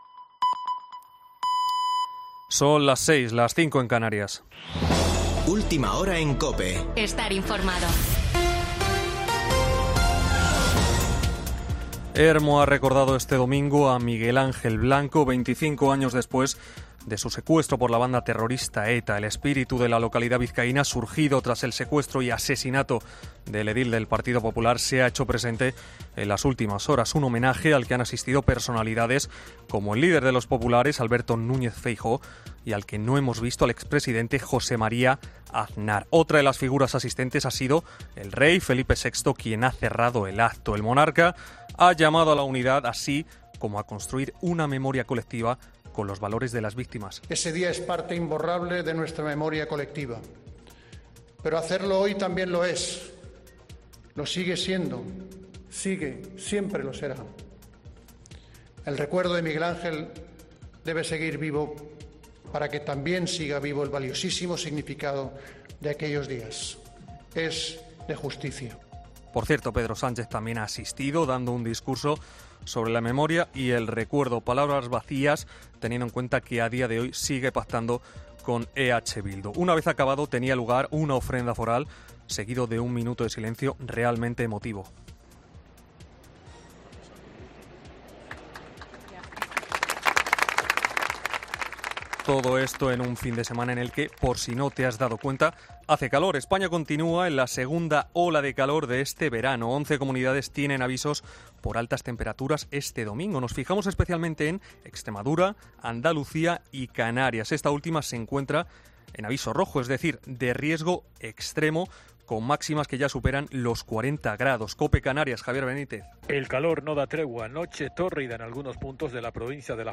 Boletín de noticias de COPE del 10 de julio de 2022 a las 18:00 horas